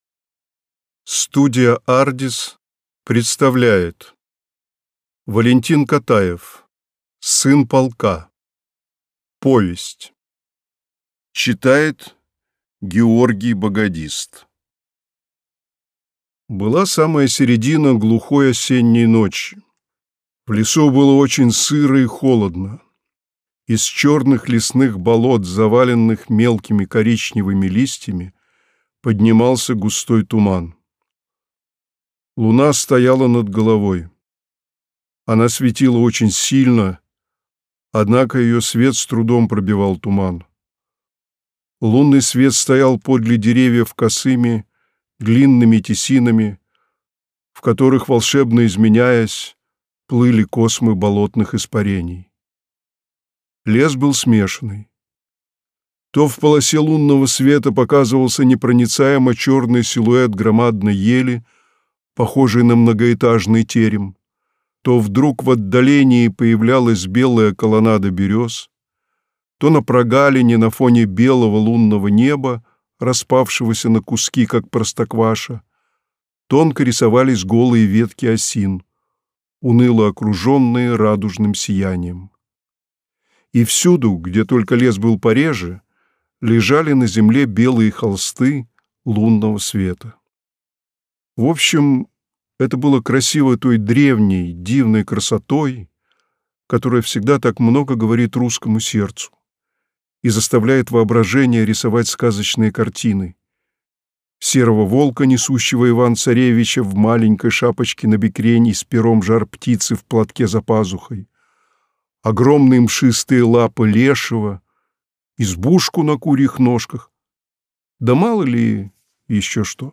Аудиокнига Сын полка | Библиотека аудиокниг